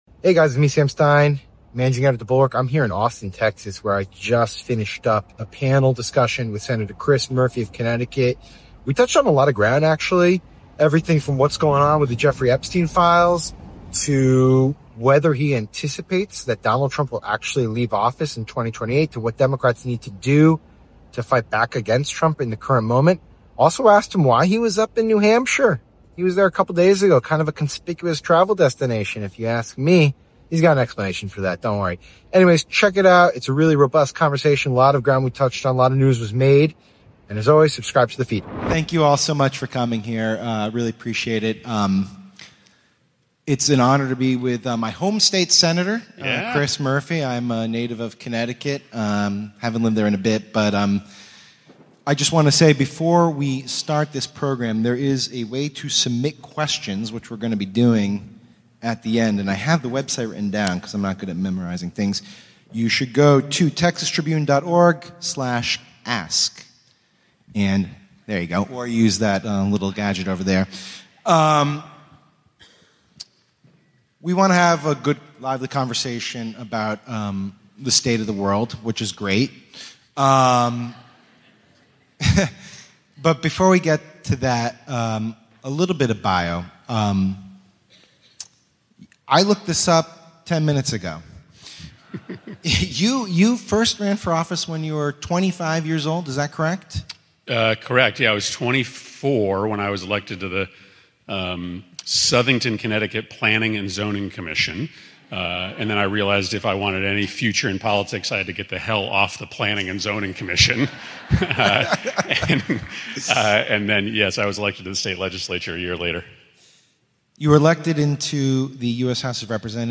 Sam Stein is joined by Sen. Chris Murphy live in Austin, TX to talk the Epstein files fight, Trump’s threats to democracy and abuse of pardons, shutdown politics, and how Democrats should respond.
Plus, an audience Q&A on AI, gun violence, and rebuilding a broad pro-democracy coalition. NOTE: There are a few moments when the recording cuts out.